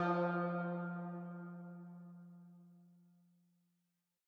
Index of /DESN275/loops/Loop Set - Spring - New Age Ambient Loops/Tail Samples
Syrup_112_C_PluckedStringTail.wav